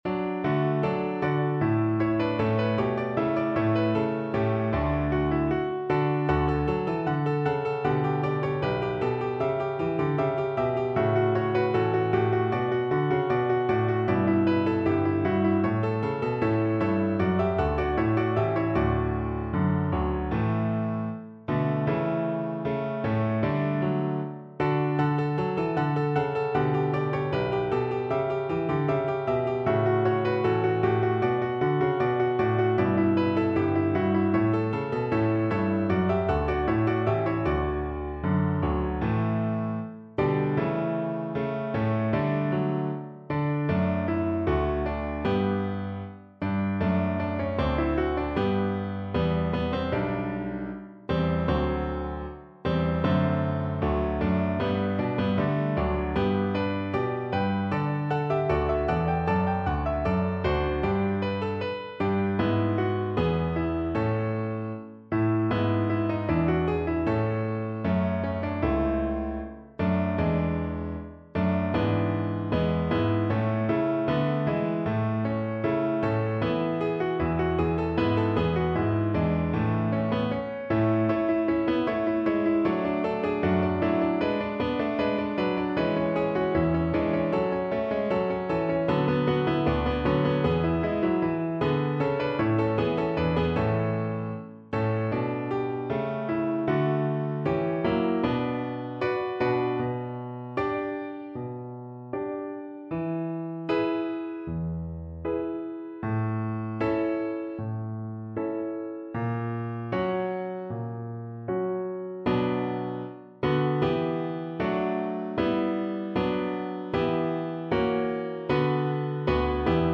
4/4 (View more 4/4 Music)
Allegro (View more music marked Allegro)
Classical (View more Classical Violin Music)